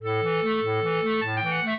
clarinet
minuet10-6.wav